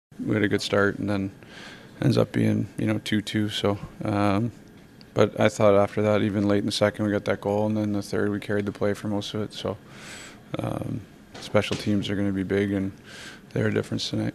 Sidney Crosby says the Penguins played good hockey but didn’t finish well.